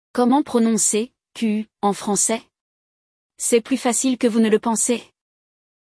Dica de pronúncia!
A letra “U” não é pronunciada, e o “Q” fica com som de /CÃN/.
A gente pronuncia /kuá/ porque o “OI” sempre tem som de /uá/!